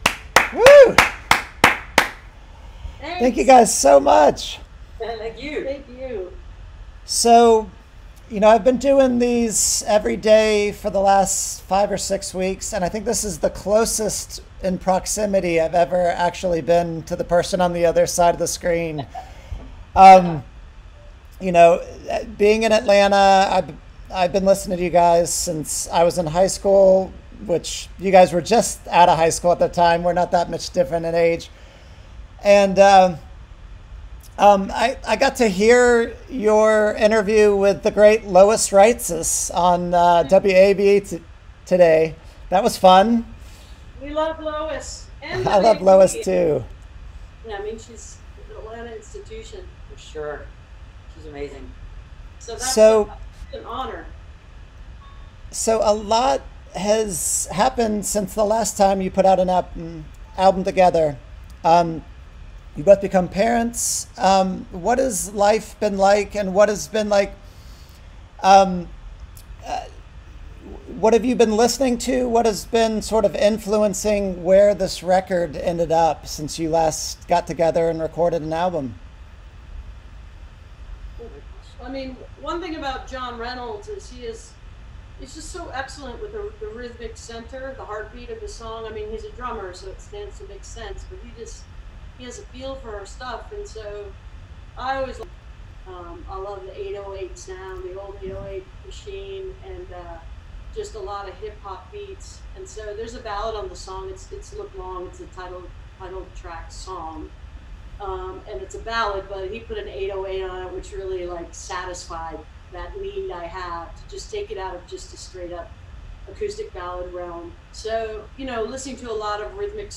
(captured from the web broadcast, including audio issues at times)
03. interview (4:31)